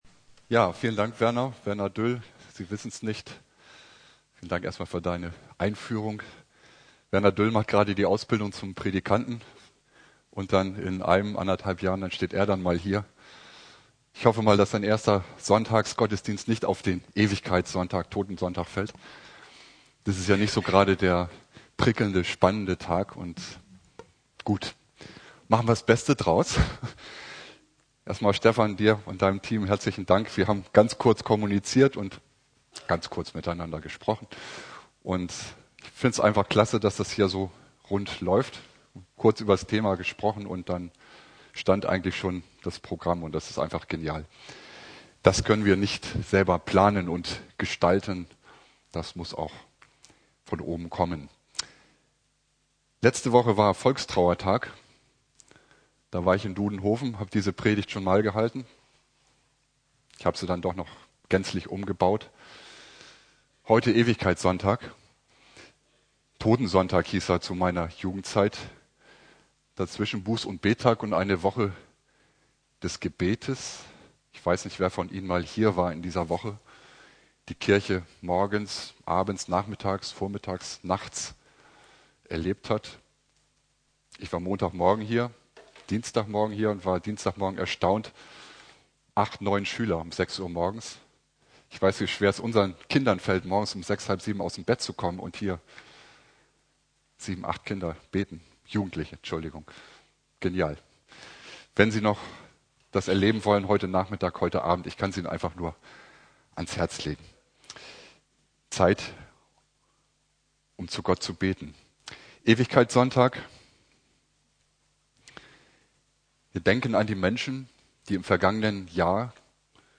Ewigkeitssonntag Prediger: Prädikant